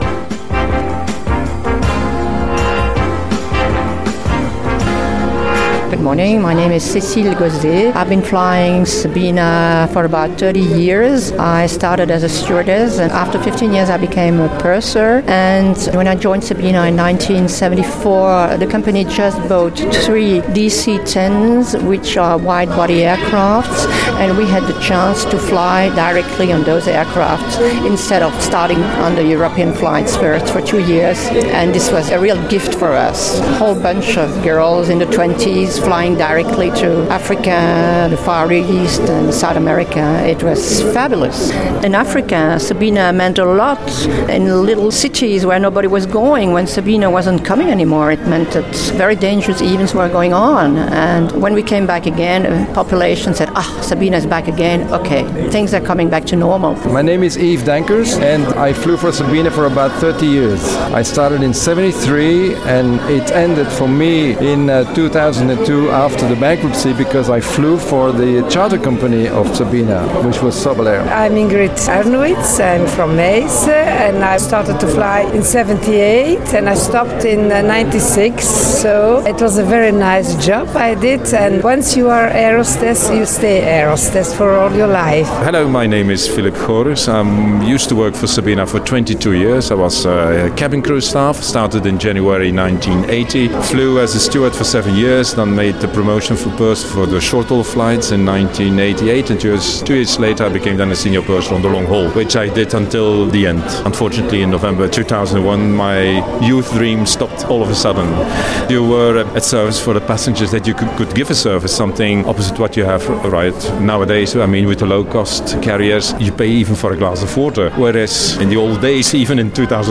At the exhibit opening